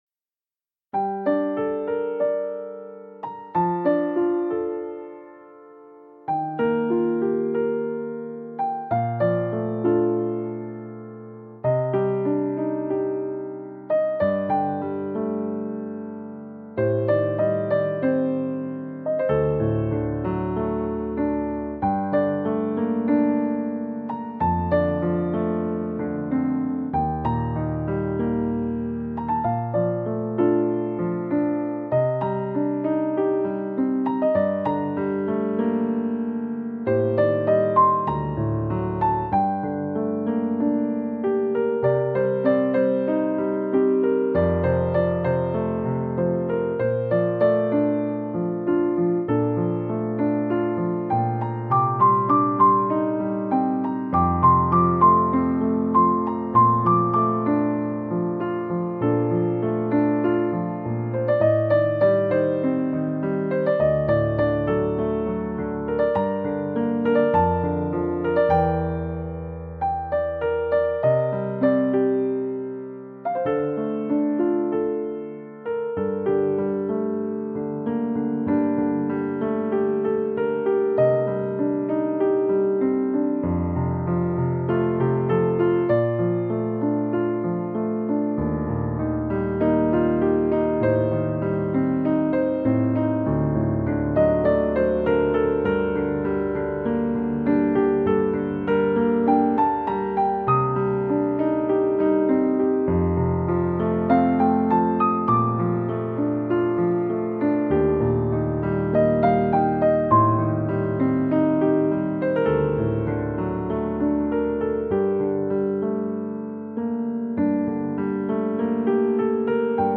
• 纯音乐 一封家书.mp3